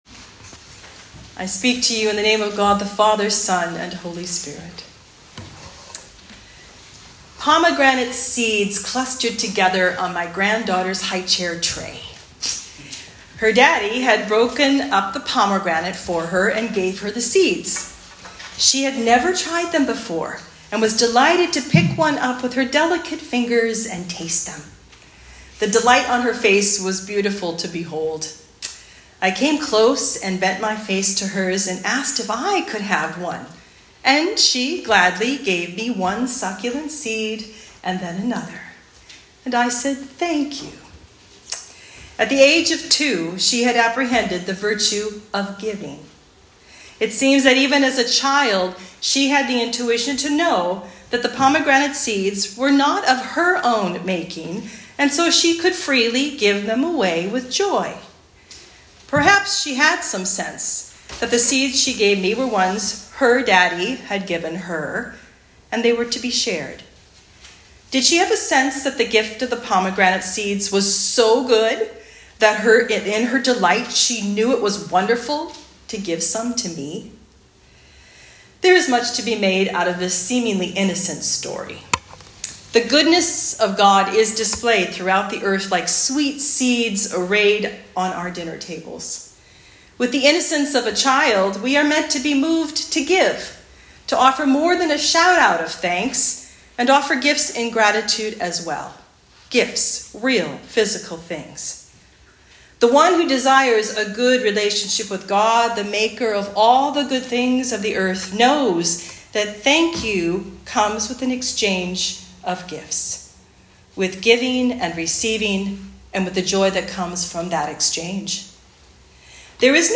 Sermons | Holy Trinity North Saanich Anglican Church
Talk on Psalm 100